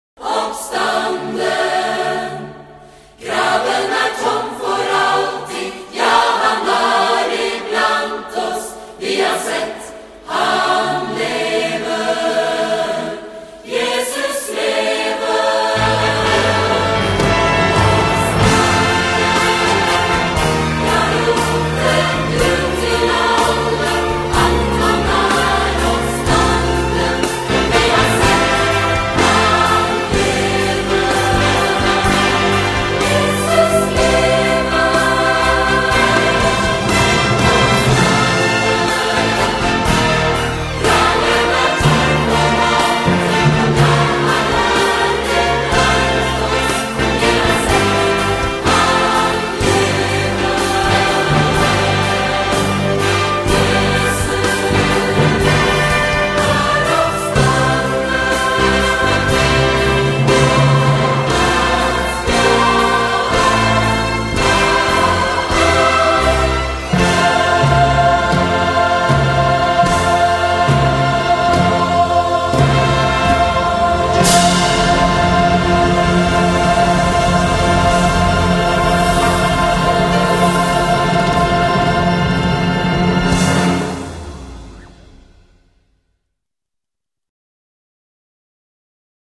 Cantata for:
Choir, symphony orchestra, rhythm and horn section